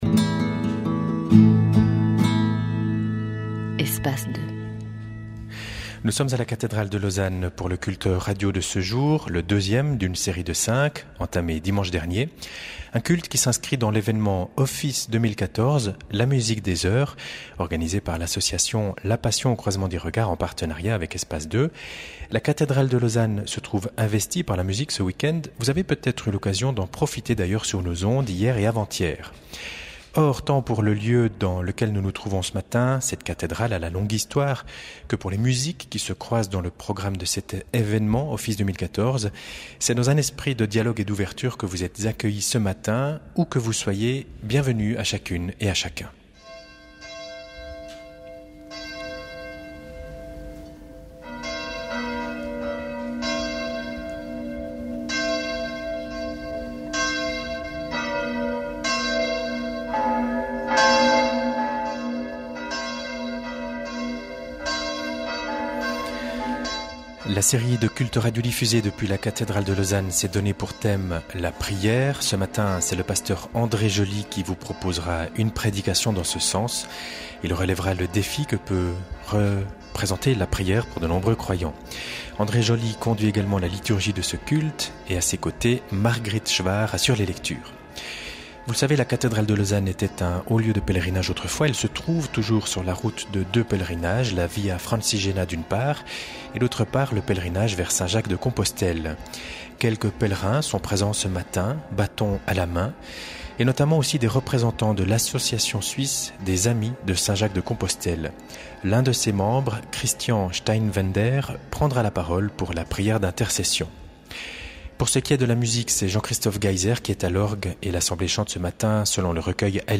Pilgergottesdienst in der Kathedrale von Lausanne, am 2. Feb. 2014 :
Culte jacquaire à la cathédrale de Lausanne, le 2 février 2014 : pour écouter, cliquez : ici | | Pilgergottesdienst in der Kathedrale von Lausanne, am 2.